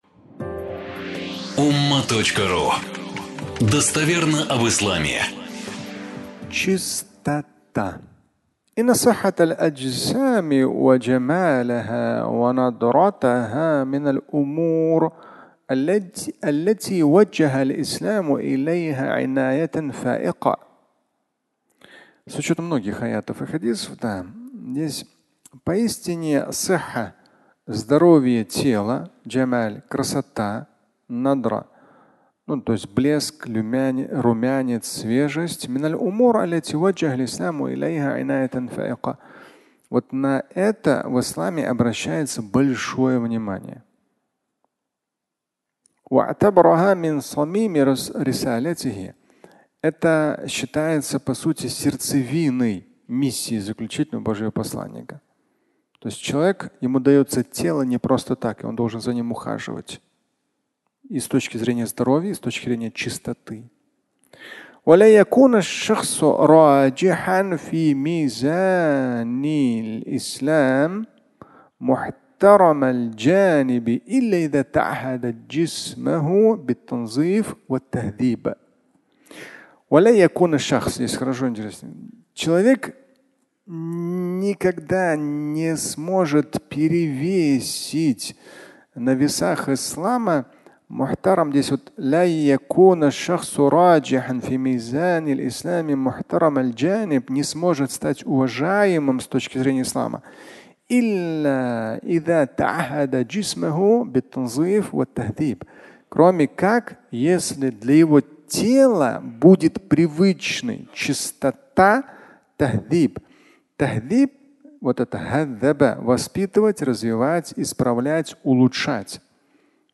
Чистота (аудиолекция)
Фрагмент пятничной лекции